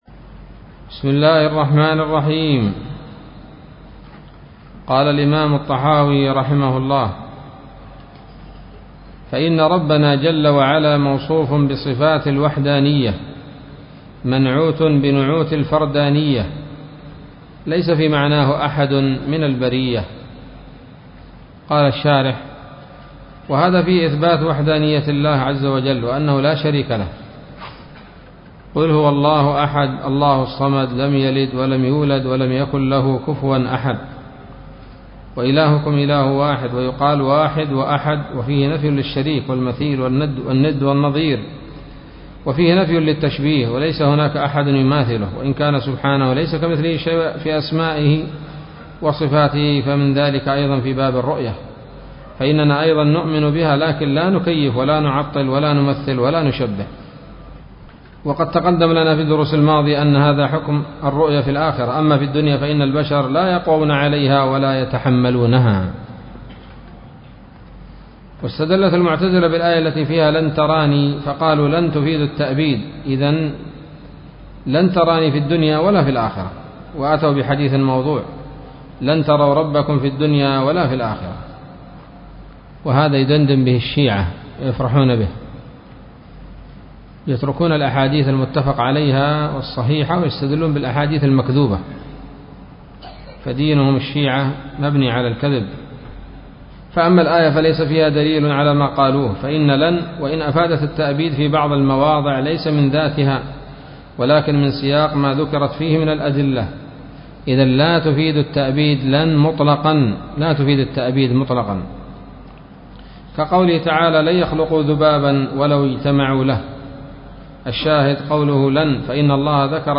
الدرس السابع والثلاثون